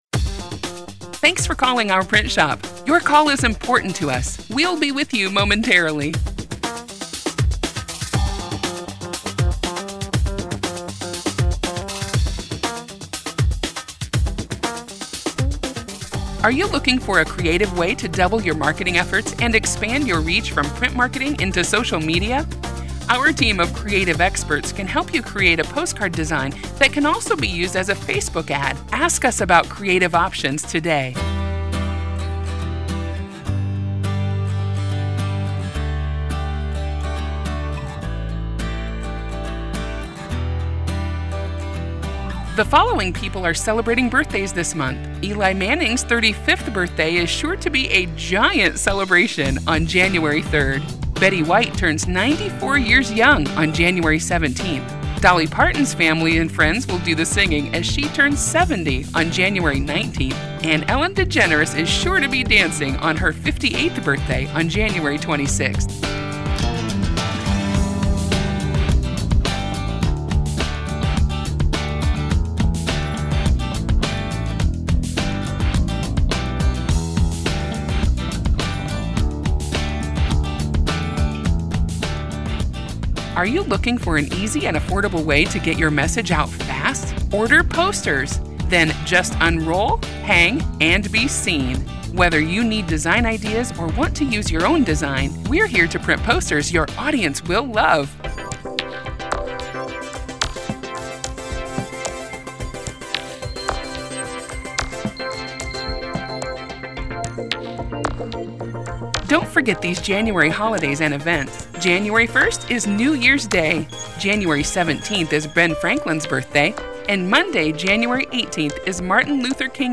• Each month, we’ll provide you with two all-new, professionally produced advertising and music on hold audio files.
Female Voice Sample
advertising-on-hold-female-sample.mp3